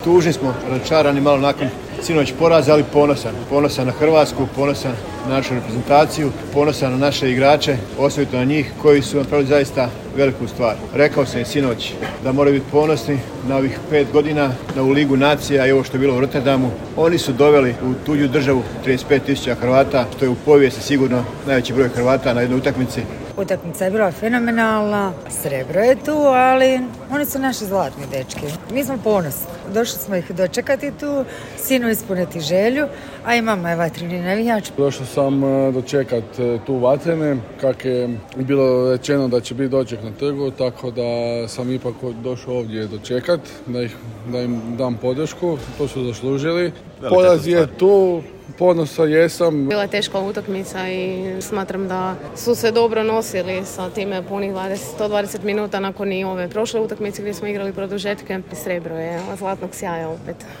Poslušajmo što je o svemu rekao izbornik Zlatko Dalić, ali i što su mi rekli ponosni građani.